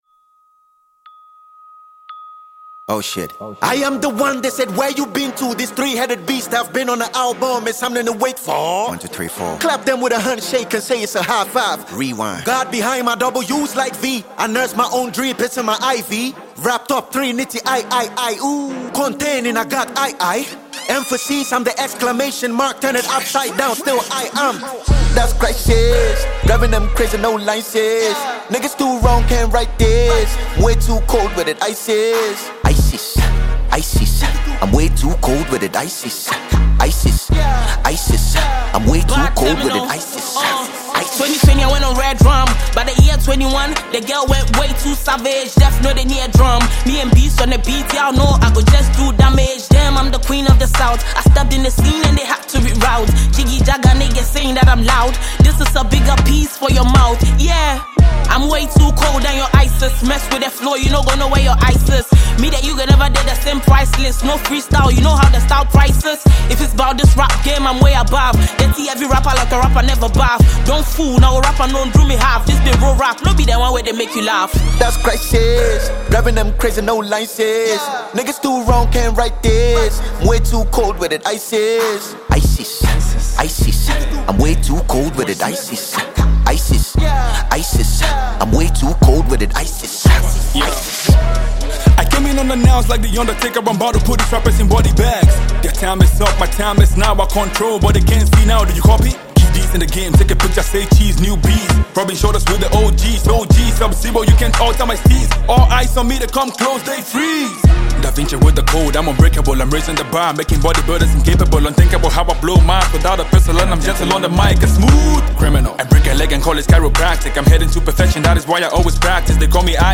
Ghanaian award-winning rapper